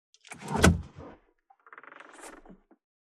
451ドアを開ける,
ドア効果音